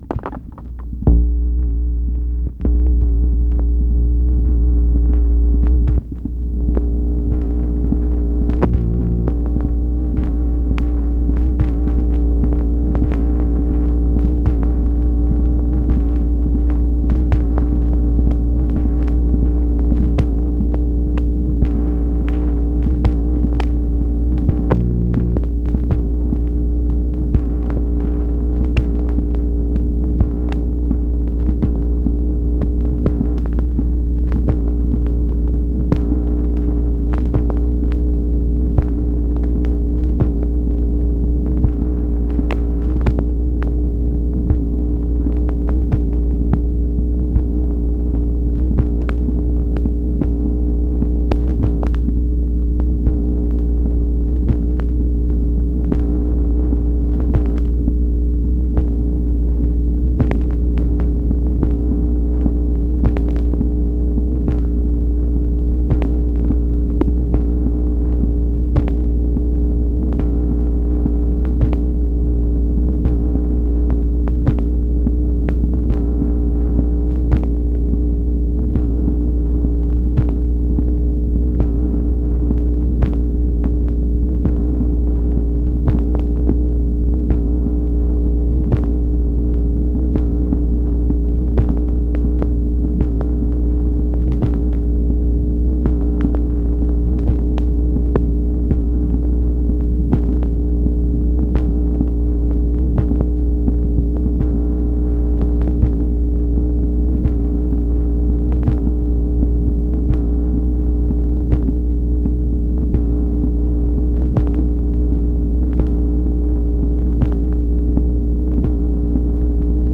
MACHINE NOISE, February 7, 1964